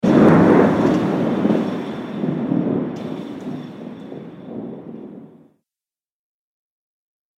دانلود آهنگ طوفان 25 از افکت صوتی طبیعت و محیط
دانلود صدای طوفان 25 از ساعد نیوز با لینک مستقیم و کیفیت بالا
جلوه های صوتی